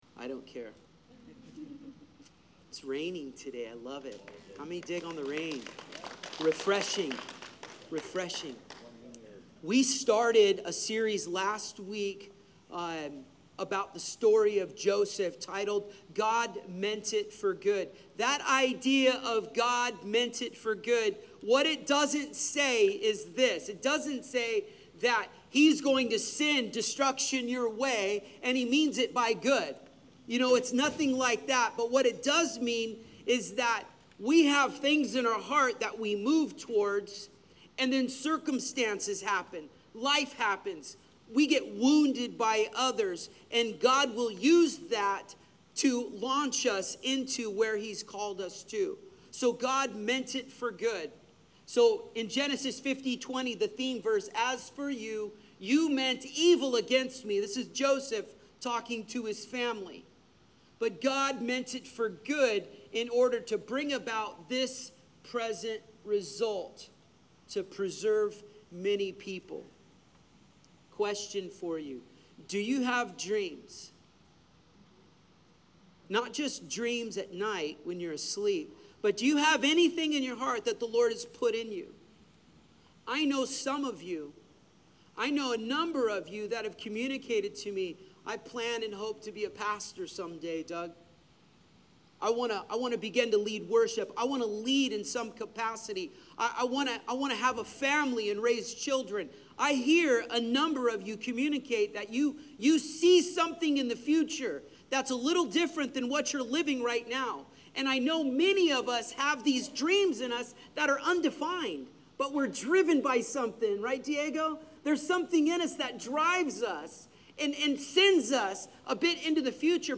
Sermon Series: God Meant It for Good — The Journey of Joseph